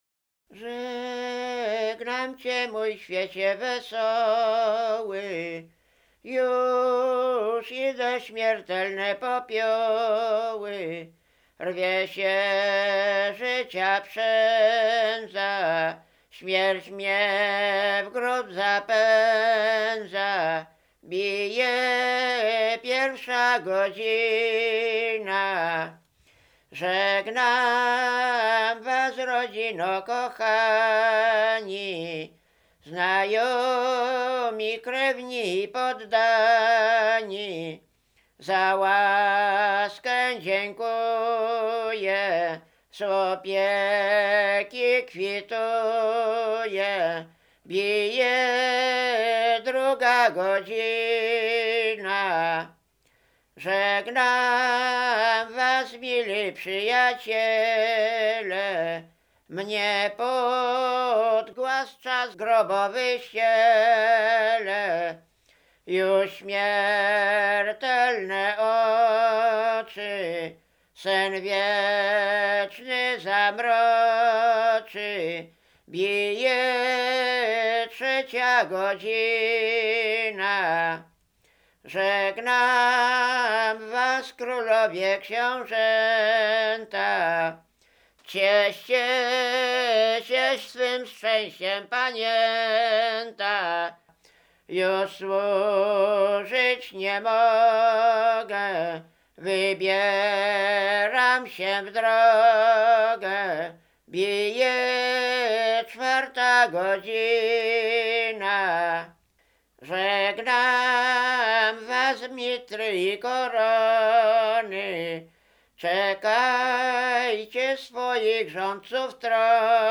Roztocze
województwo lubelskie, powiat janowski, gminie Godziszów, wieś Zdziłowice
Pogrzebowa
pogrzebowe nabożne katolickie